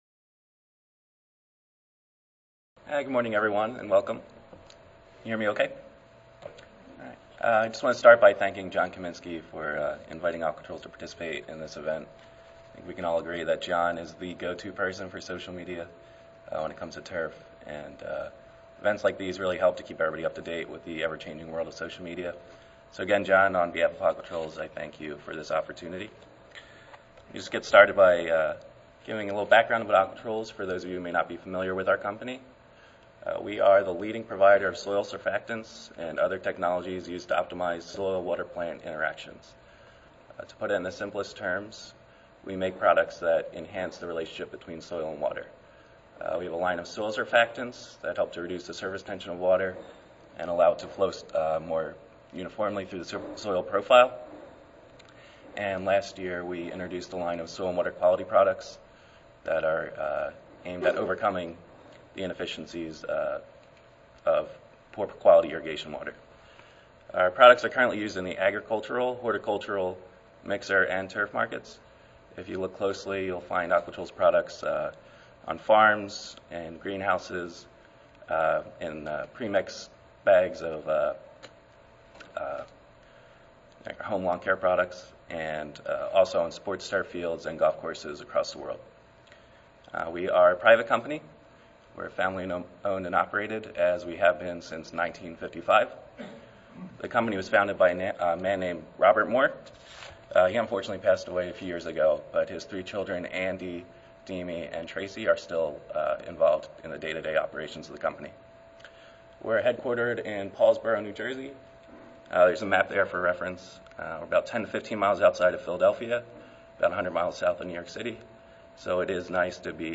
Aquatrols Recorded Presentation Audio File 8:30 AM 72-2 Can Twitter be Effective in the Classroom?.